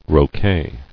[ro·quet]